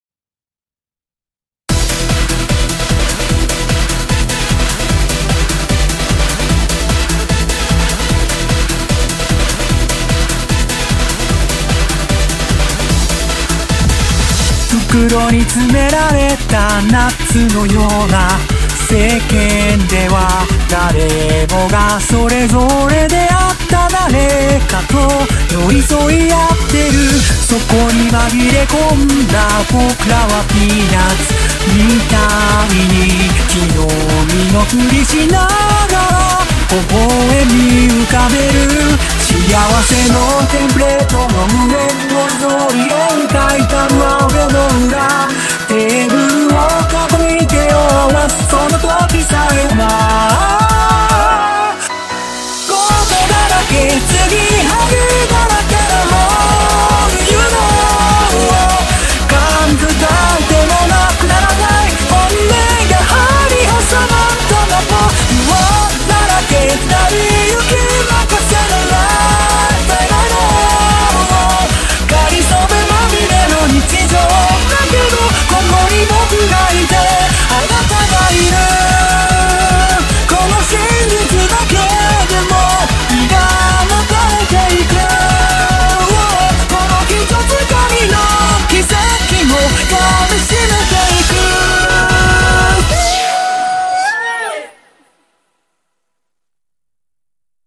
BPM150
Audio QualityPerfect (High Quality)
Mashup